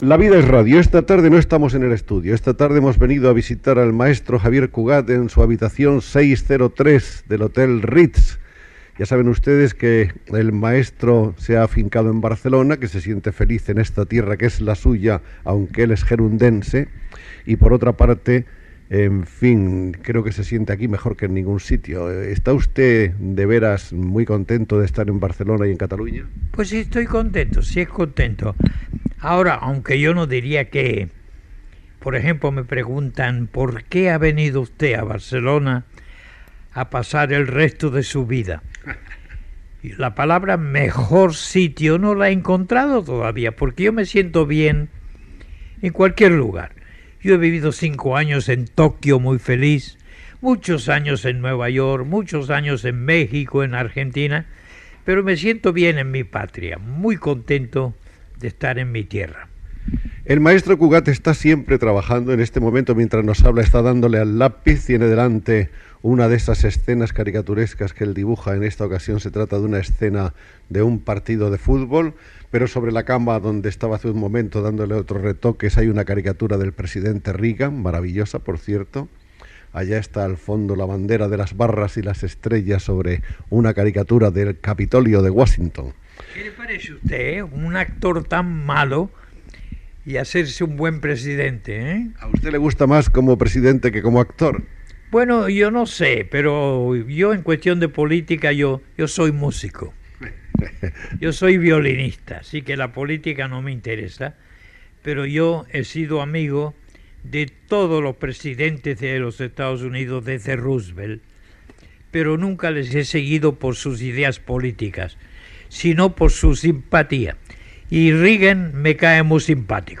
Entrevista a Xavier Cugat feta a la seva habitació de l'Hotel Ritz.
Entreteniment